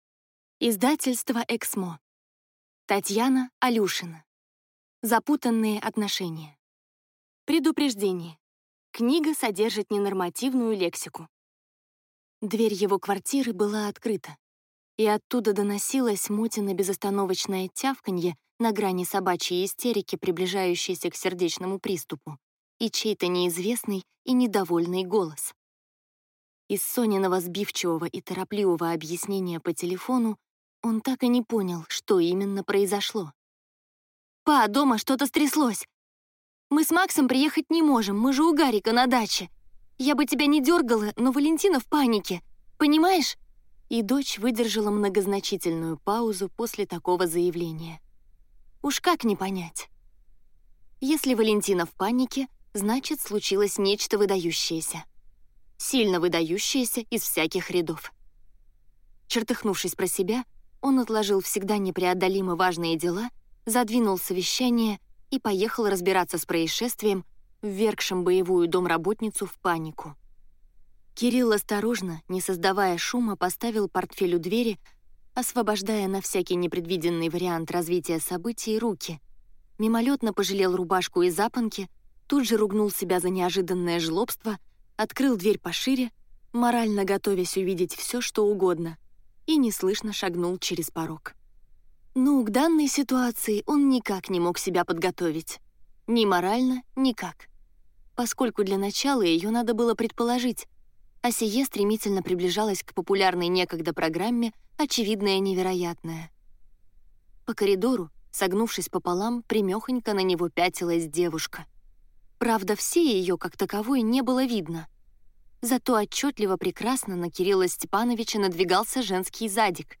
Аудиокнига Запутанные отношения | Библиотека аудиокниг